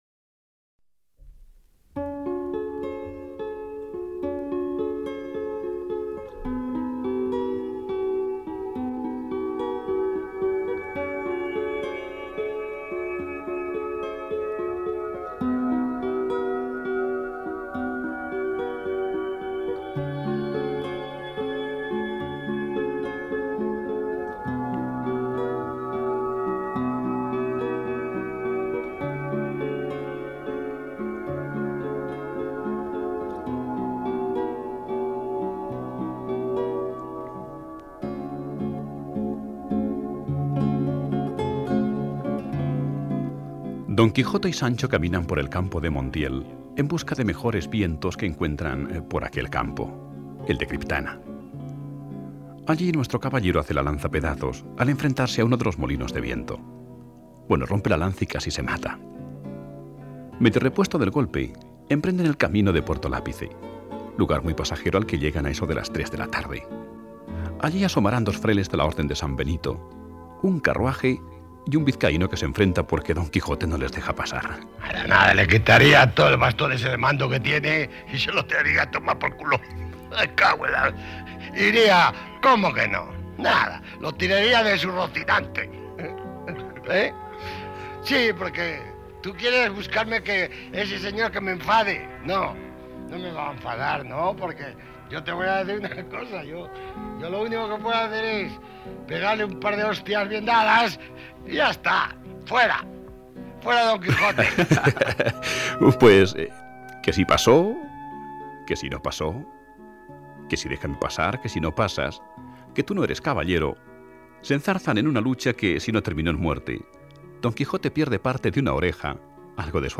Raíces. En un lugar de La Mancha te leemos los capítulos XI, XII y XII del Quijote: pastores trashumantes, hayedos en La Mancha y un entierro